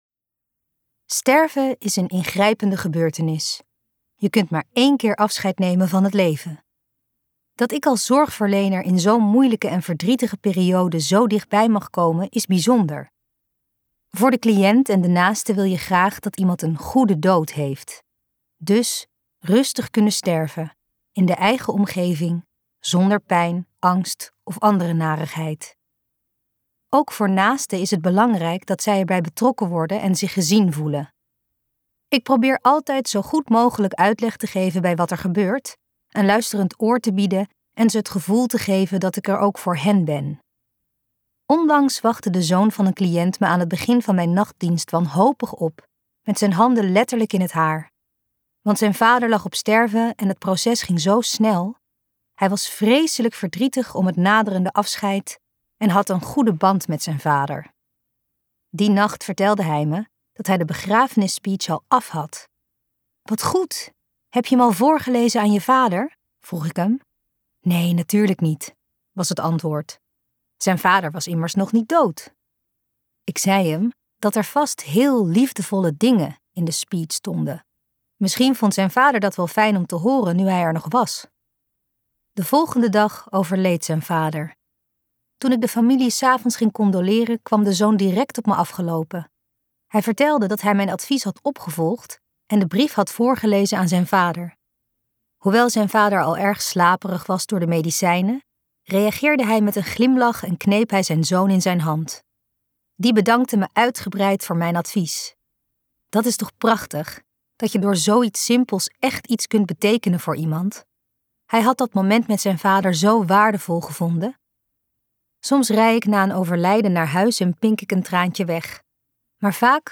Nachtdienst luisterboek | Ambo|Anthos Uitgevers